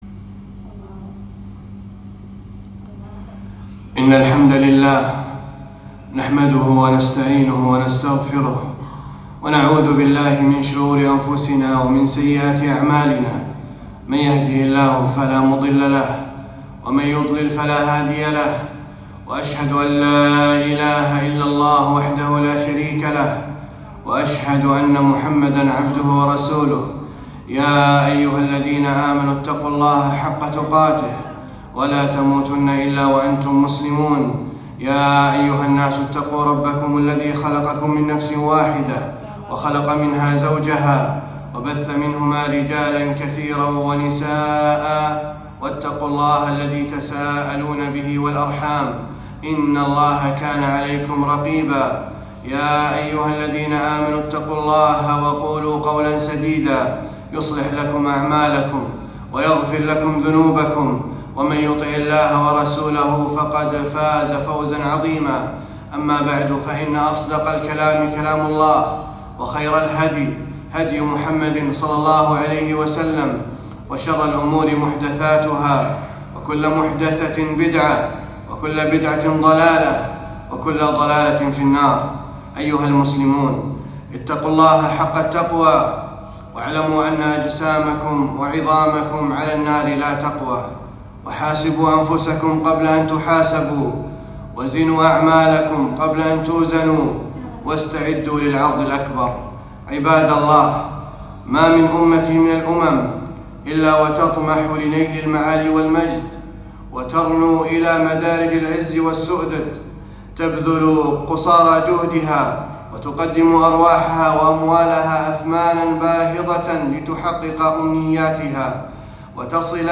خطبة - اجتماع الكلمة وطاعة ولي الأمر